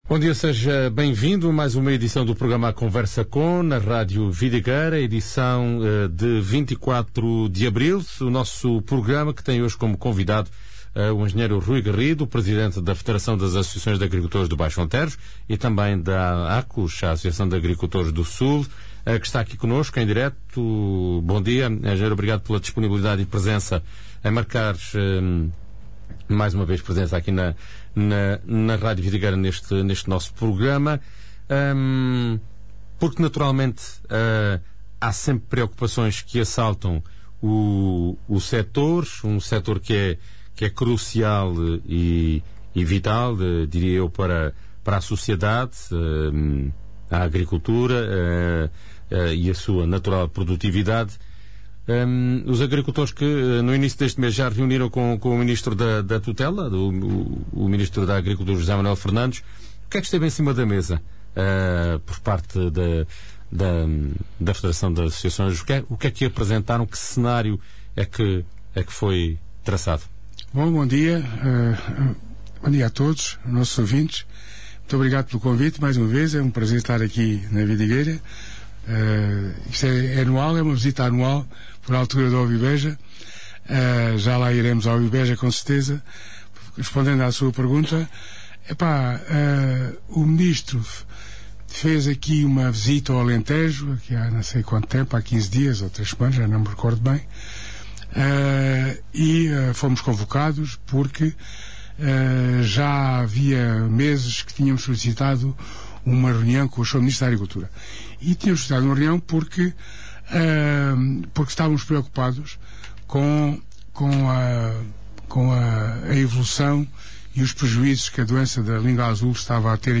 A entrevista